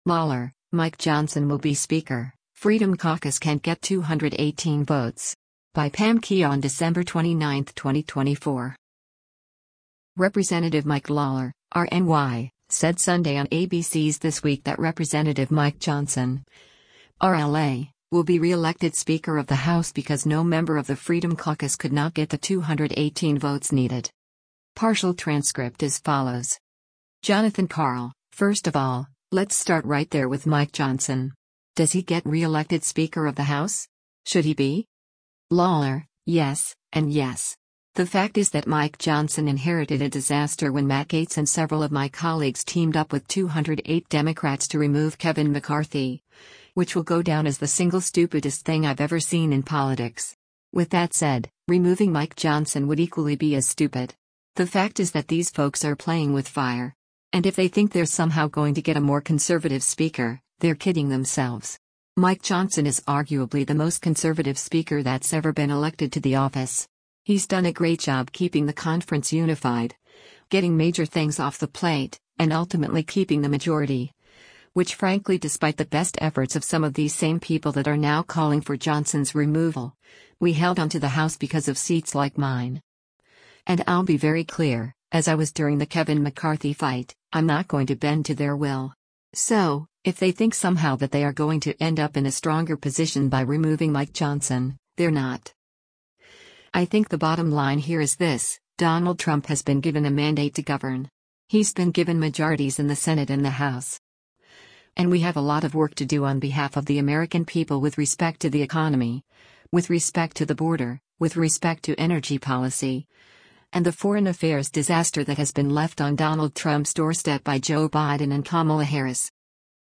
Representative Mike Lawler (R-NY) said Sunday on ABC’s “This Week” that Rep. Mike Johnson (R-LA) will be reelected Speaker of the House because no member of the Freedom Caucus could not get the 218 votes needed.